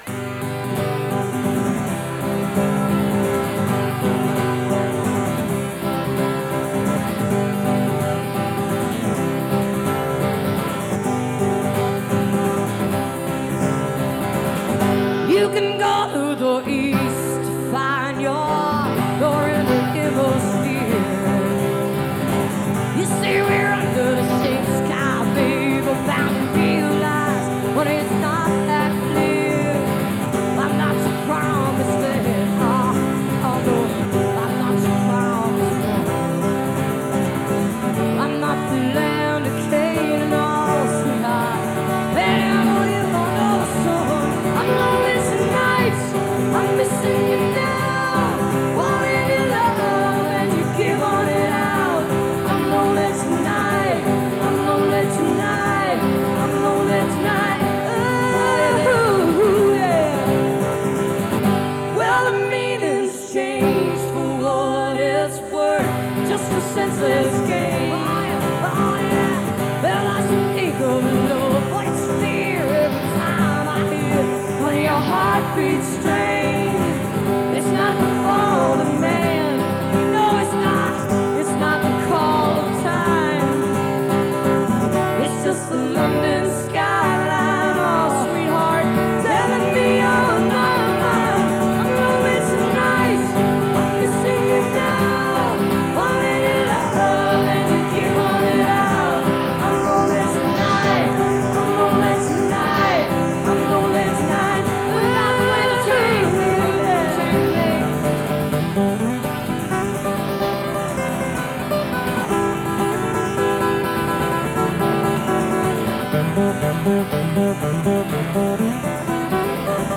(radio broadcast source)